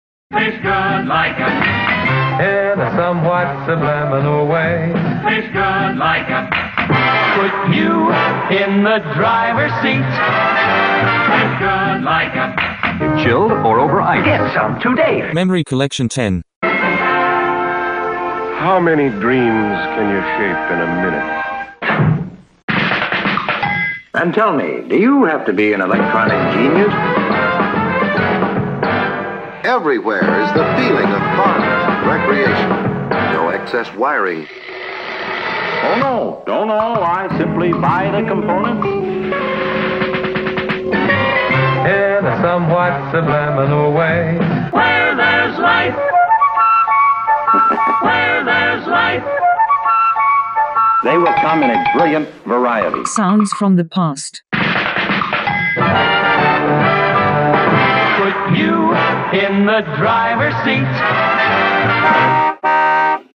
Samples include voice/spoken word/dialogs, sfx/foley and music.
Official (parody) advertisement